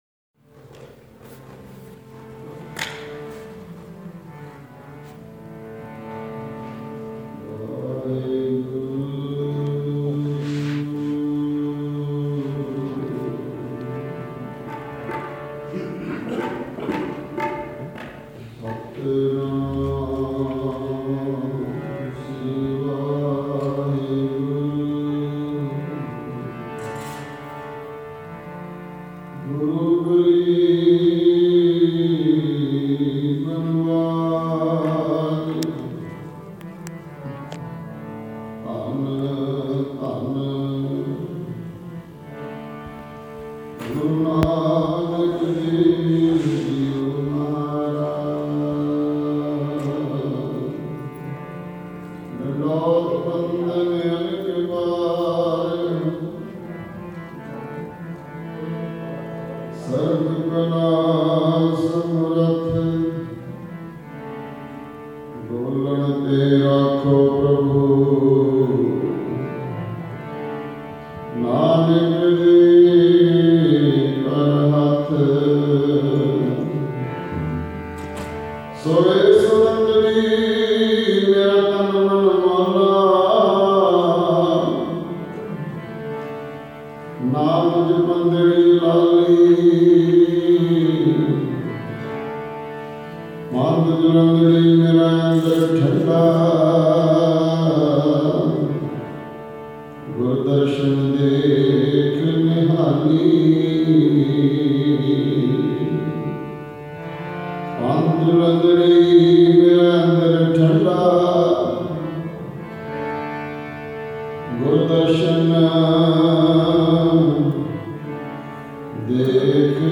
Roohani Kirtan – Nanaksar Gurdwara, Toronto – Day 1